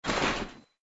audio: Converted sound effects
GUI_create_toon_store.ogg